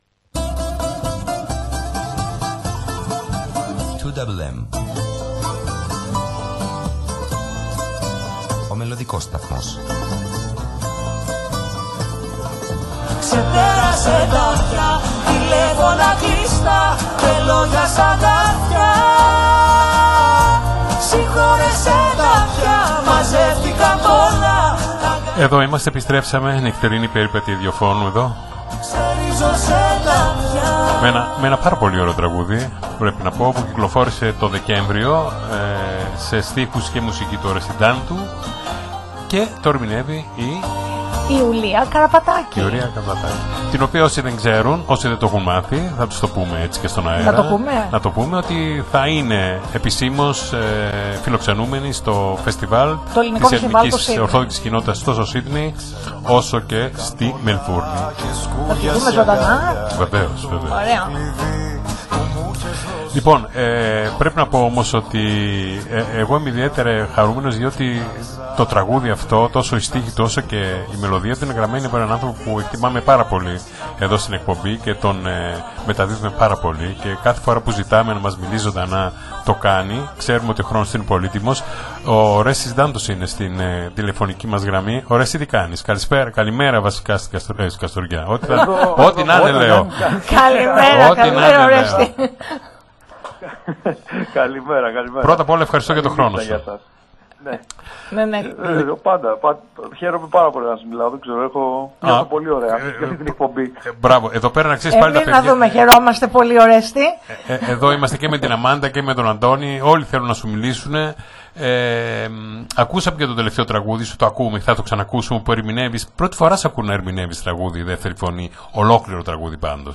Η συνέντευξη παραχωρήθηκε στους